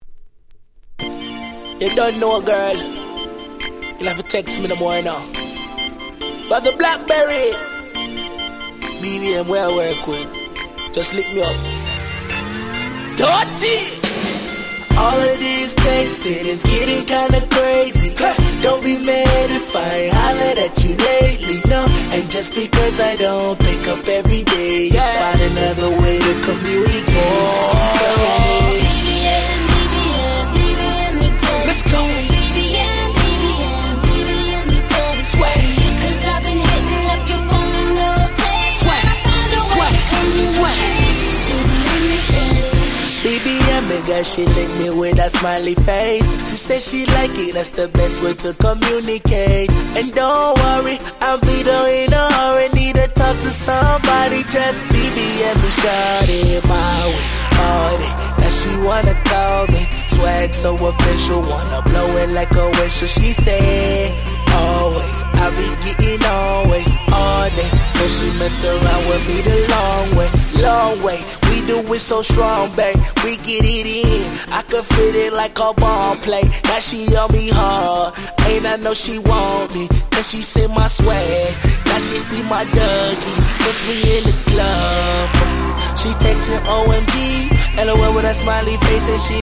10' Smash Hit R&B !!